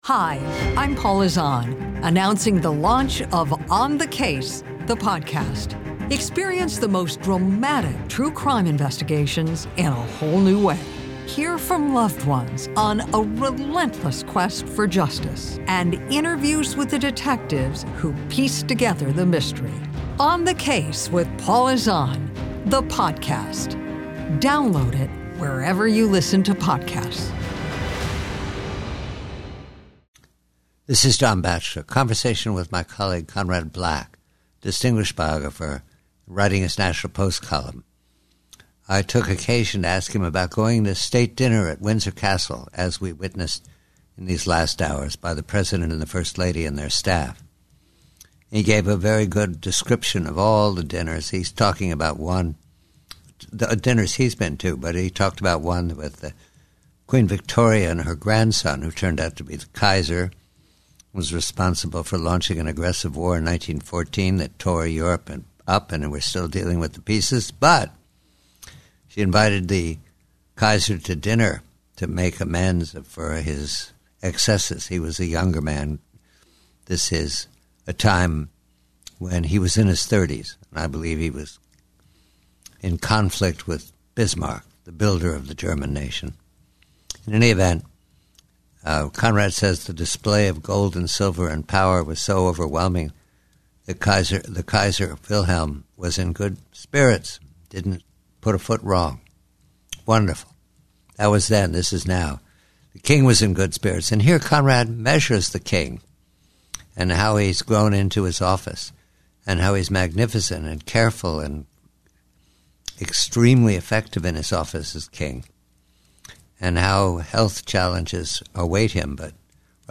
Headliner Embed Embed code See more options Share Facebook X Subscribe PREVIEW: GUEST NAME: Conrad Black SUMMARY: John Batchelor converses with Conrad Black about King Charles III's growth into his office. Black describes a dignified state dinner at Windsor Castle , contrasting it with a historical one involving Queen Victoria and Kaiser Wilhelm . Despite health challenges, Black praises King Charles's effective, high-end performance as king, noting his conduct with utmost dignity.